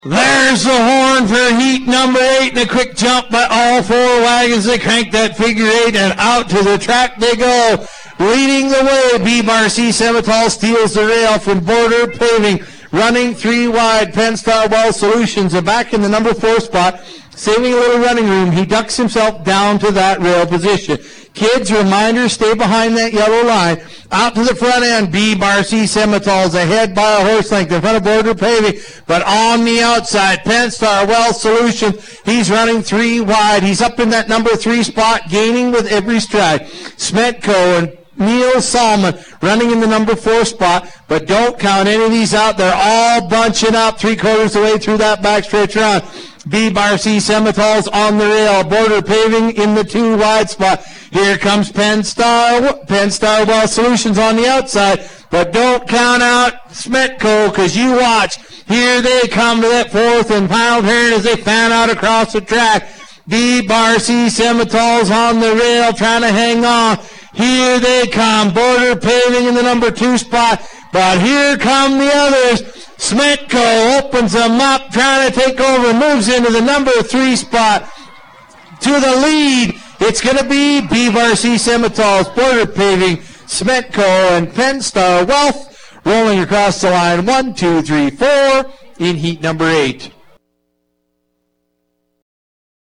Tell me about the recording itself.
Night-1-Heat-8.mp3